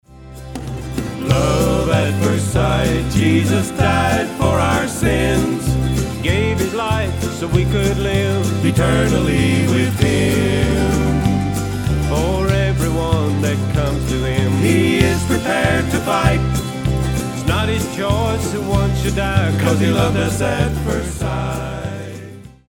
Autoharp, Lead & Harmony Vocals
Guitar